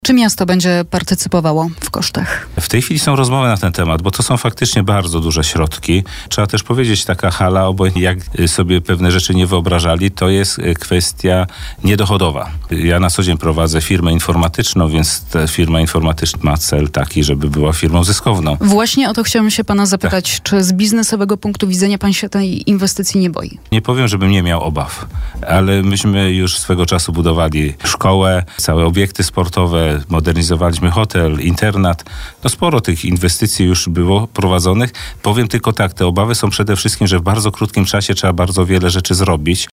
– Przede wszystkim będzie to inwestycja w obiekt czysto sportowy, który spełnia wymogi rozgrywek międzynarodowych. To nie będzie jakaś olbrzymia hala. To będzie hala, w której siedzących miejsc będzie 500, stojących 250 – mówił gość naszej porannej audycji (całość rozmowy do odsłuchania w zakładce audycje/gość Radia Bielsko).